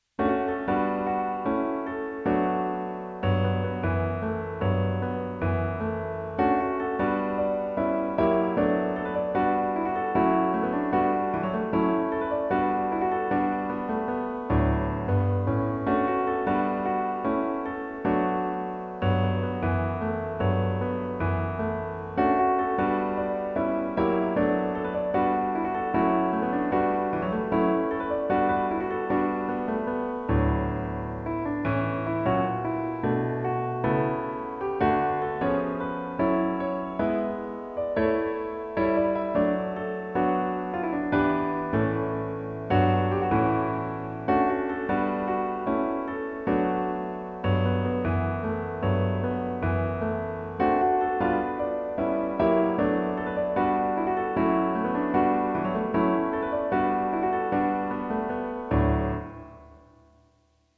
Basic Solo Piano Arrangements
Basic Piano Arrangement